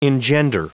Prononciation du mot engender en anglais (fichier audio)
Prononciation du mot : engender